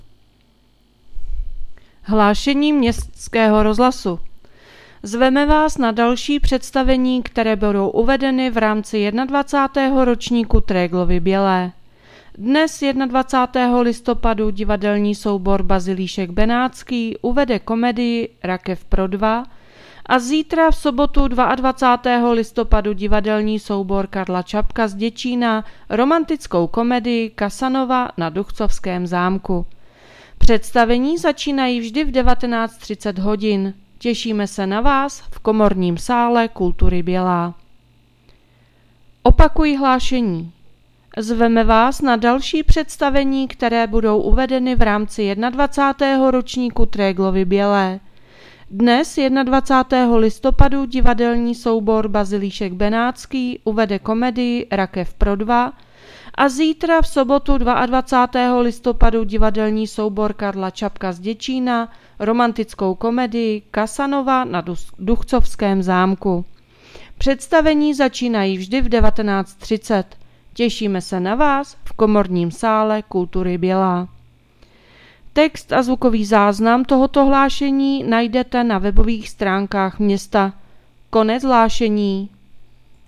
Hlášení městského rozhlasu 21.11.2025 | Město Bělá pod Bezdězem